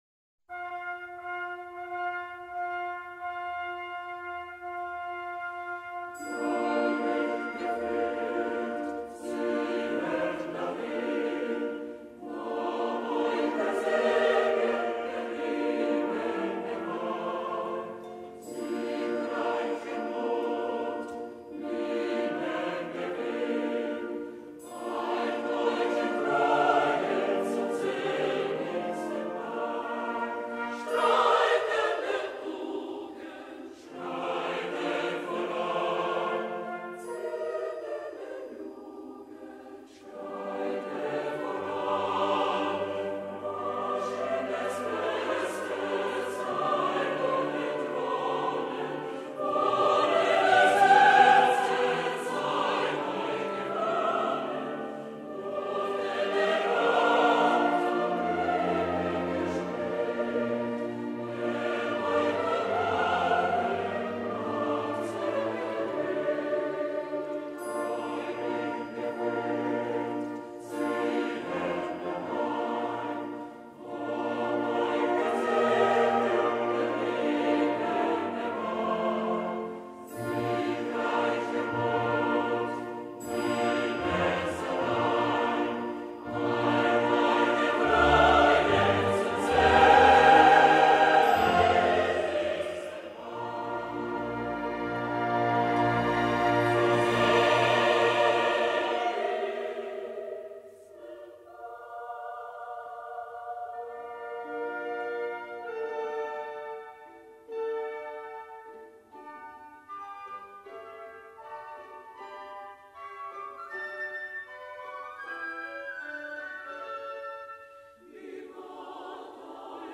6) 행진곡(March) - 군대 또는 집단을 질서바르게 행진시키기 위한 실용음악.
실용음악으로서의 행진곡은 단순 명쾌한 리듬과 규칙적인 프레이즈로 되는 것이 원칙이다.
주로 4박자나 2박자(빠른 6/8박자 포함)를 사용하며 그 사용 목적에 따라 군대행진곡, 결혼행진곡, 장송행진곡, 개선행진곡, 축전행진곡 등 여러 가지로 불린다.